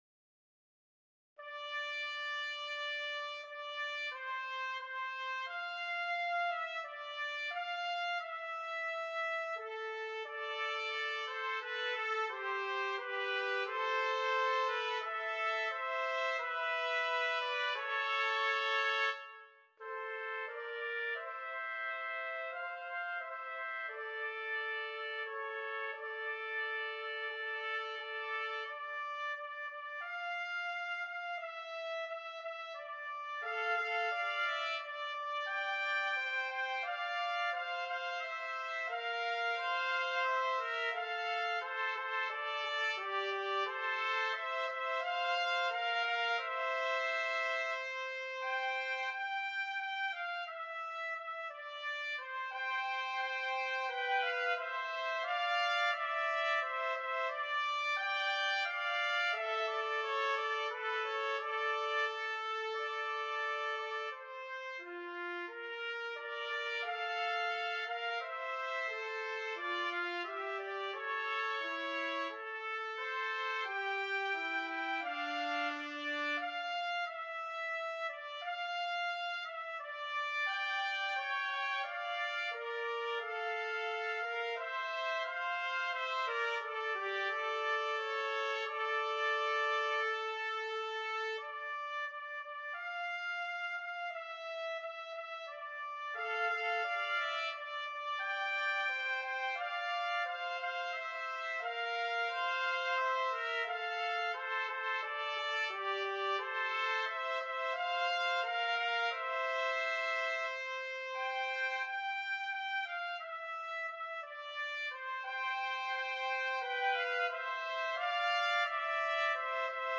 Hear the Voice and Prayer trpt. duet